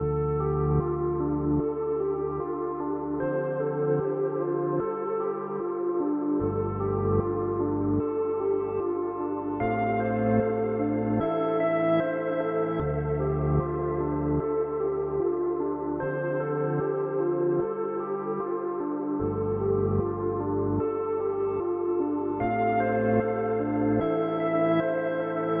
描述：调D小调 给我一个你工作的链接。
标签： 150 bpm Trap Loops Pad Loops 4.31 MB wav Key : D
声道立体声